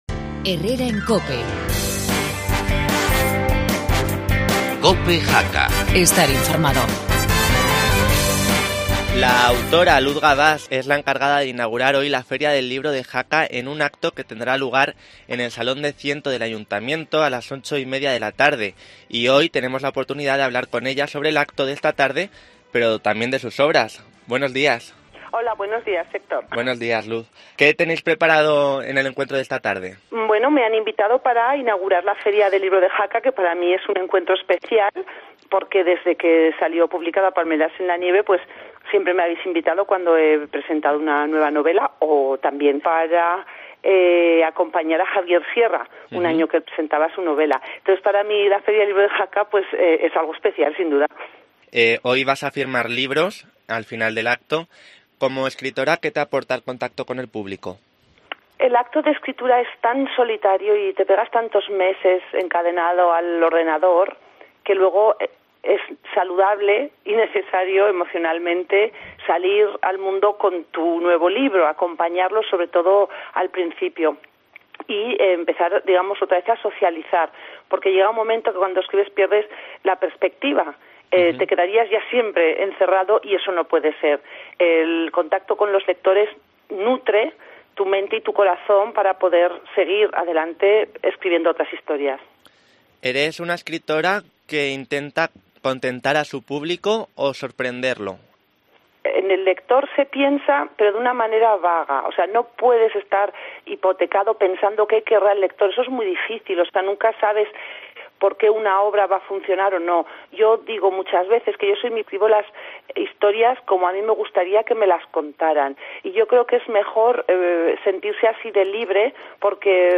Entrevista Luz Gabás